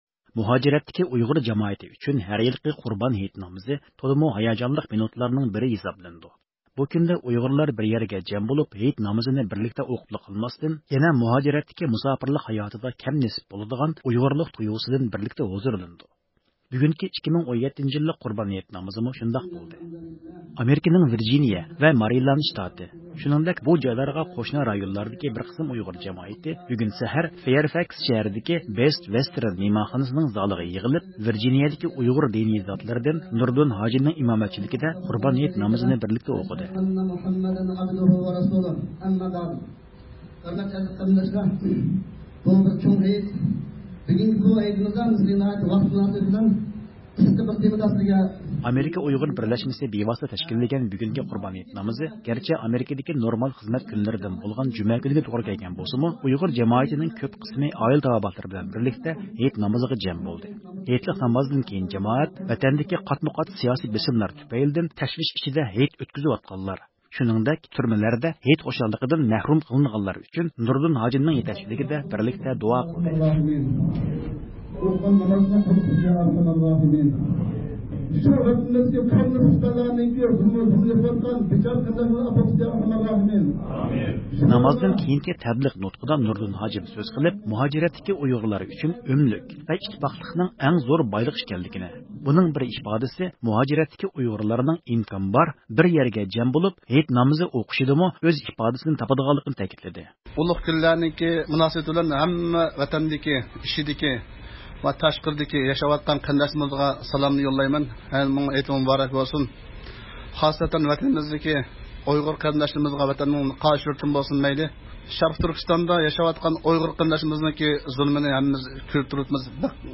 بۇ يىلقى قۇربان ھېيت مۇناسىۋىتى بىلەن دۇنيا ئۇيغۇر قۇرۇلتىيىنىڭ ئالىي رەھبىرى رابىيە قادىر خانىم رادىيومىز ئارقىلىق ۋەتەندىكى ئۇيغۇرلارغا ئۆزىنىڭ ئوتلۇق سالىمىنى، شۇنداقلا تىلەكلىرىنى يەتكۈزۈپ قويۇشنى ھاۋالە قىلدى.
دۇنيا ئۇيغۇر قۇرۇلتىيىنىڭ سابىق رەئىسى، ئۇيغۇر مىللىي داۋاسىنىڭ پېشقەدەملىرىدىن ئەركىن ئالىپ تېكىن ئاكا ۋەتەن ئىچى-سىرتىدىكى بارلىق ئۇيغۇرلارغا ئۆزىنىڭ قۇربان ھېيتلىق سالىمىنى يوللىدى.